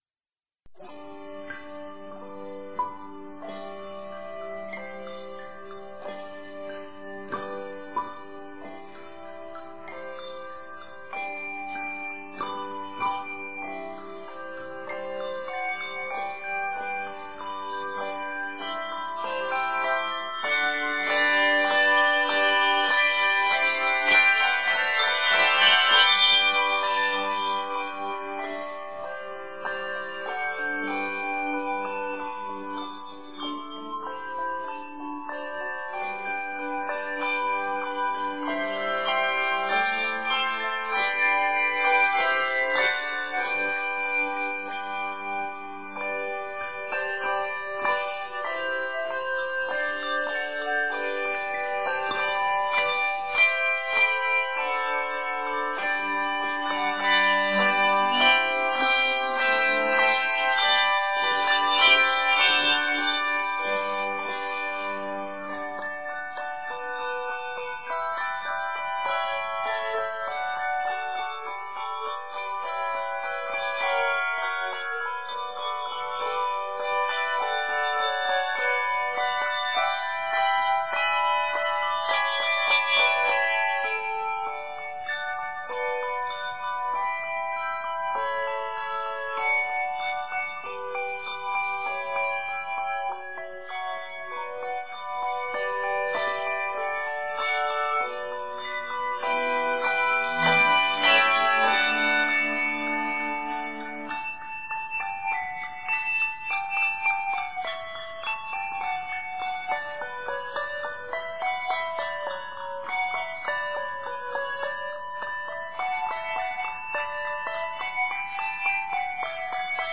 Much of the piece is chordal.